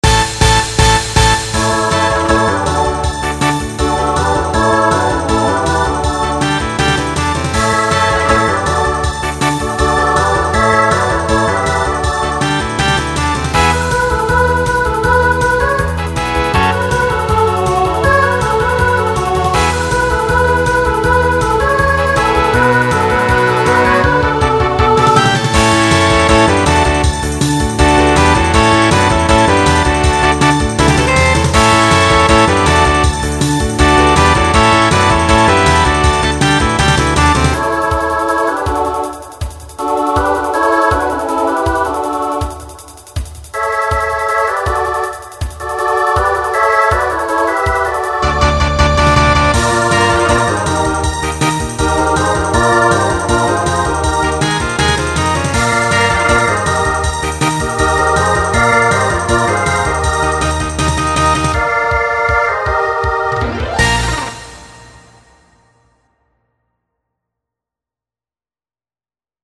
Genre Rock Instrumental combo
Transition Voicing SSA